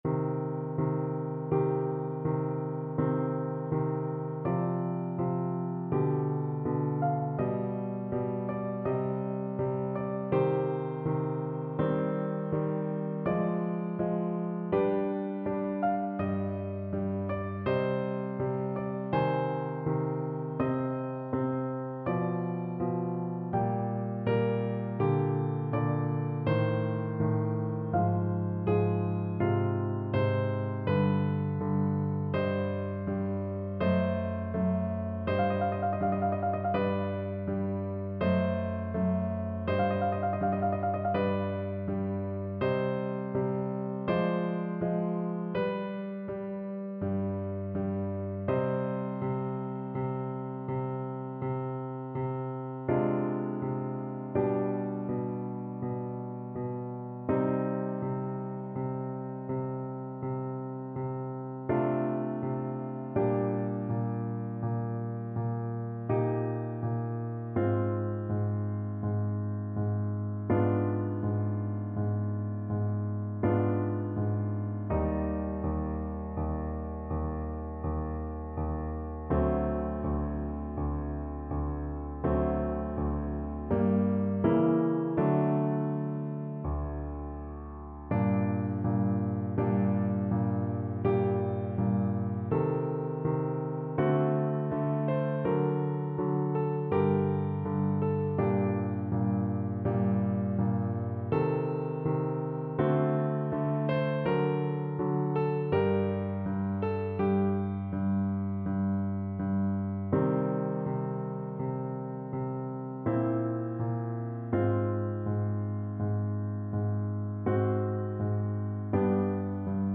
Classical Vivaldi, Antonio Stabat Mater, RV 621, 1st Movement French Horn version
C minor (Sounding Pitch) G minor (French Horn in F) (View more C minor Music for French Horn )
3/4 (View more 3/4 Music)
= 56 Largo
Classical (View more Classical French Horn Music)